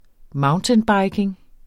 Udtale [ ˈmɑwntənˌbɑjgeŋ ]